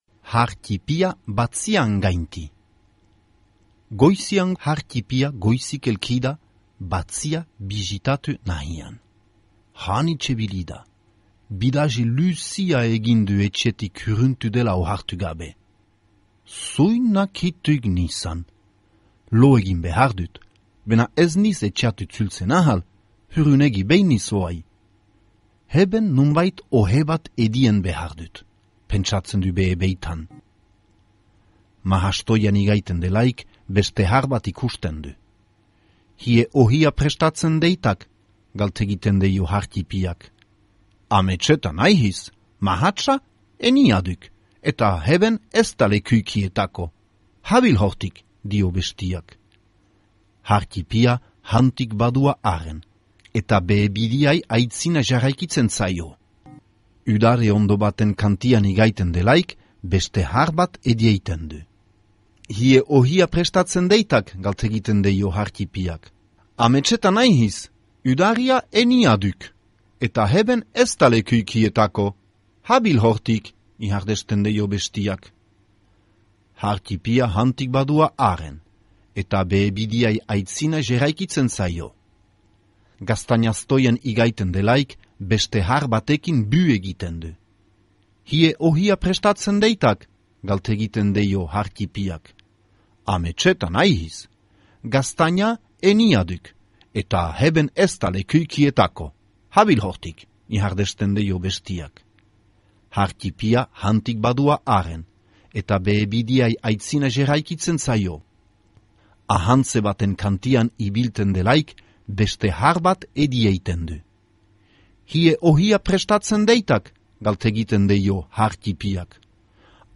Har ttipia baratzean gainti - ipuina entzungai